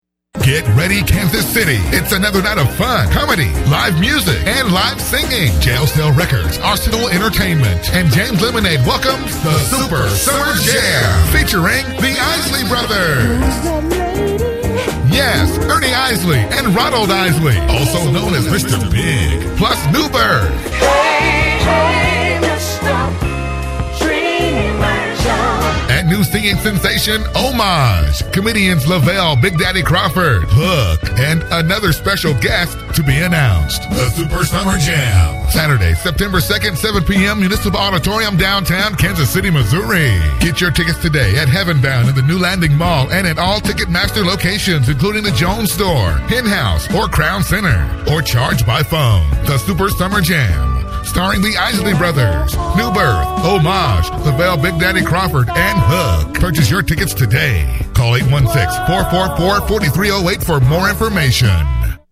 Promotional Demo
All Digital Studio